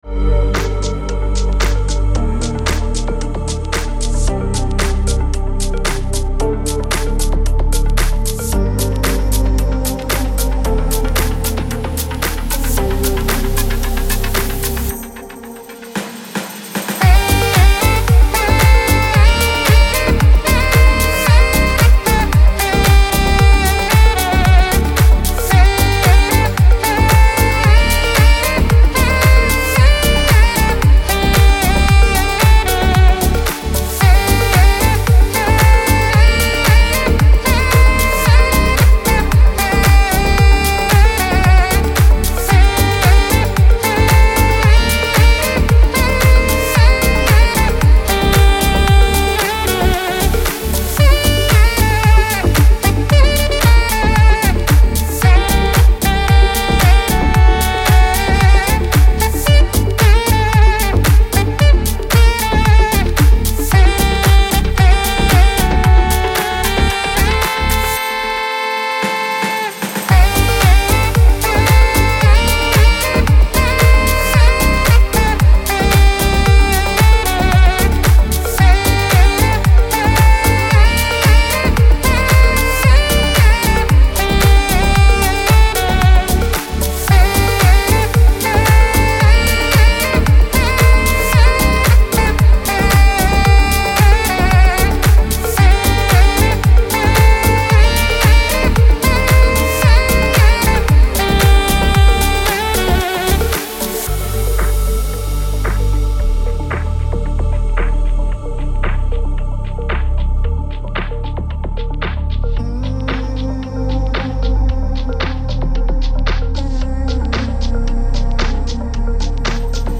رقص ریتمیک آرام موسیقی بی کلام
موسیقی بی کلام دنس موسیقی بی کلام ساکسفون